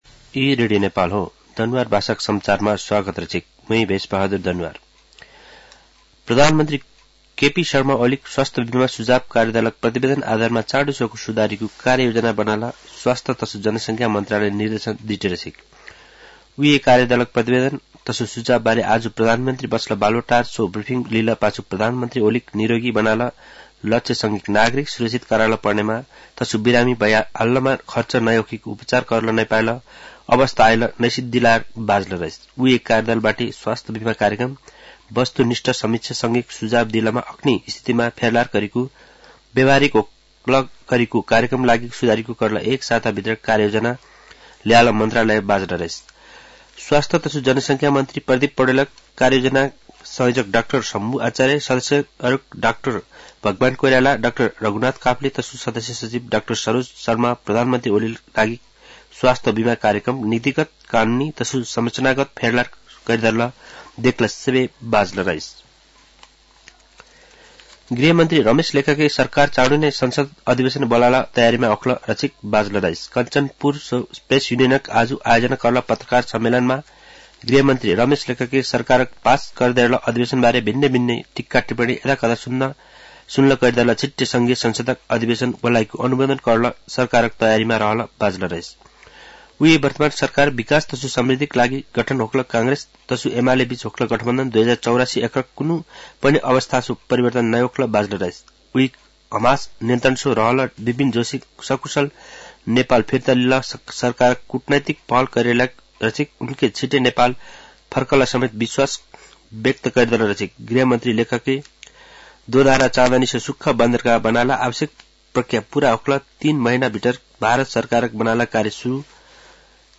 दनुवार भाषामा समाचार : ७ माघ , २०८१
Danuwar-news-6.mp3